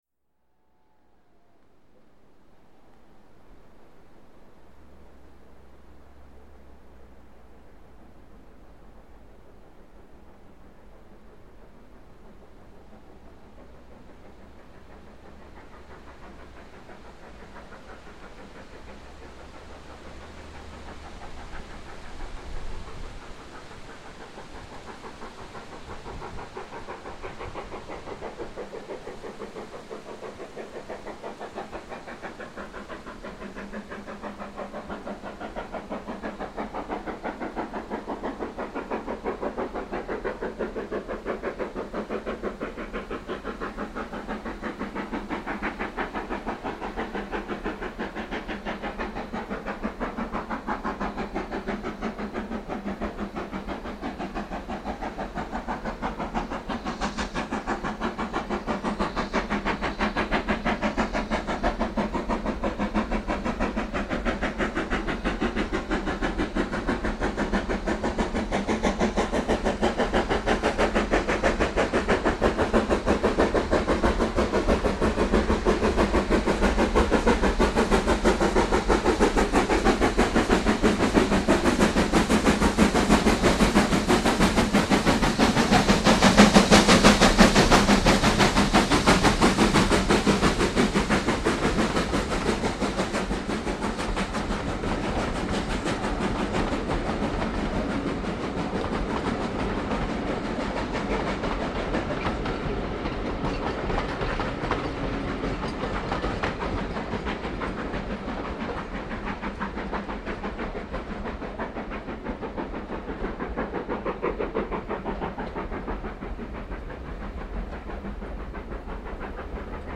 80135 im Einschnitt bei Beck Hole vor der Straßenbrücke, um 11:56h am 14.08.2000.   Hier anhören: